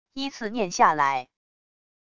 依次念下来wav音频